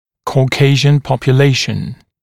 [kɔː’keɪʒən ˌpɔpju’leɪʃn][ко:’кейжэн ˌпопйу’лэйшн]популяция европеоидов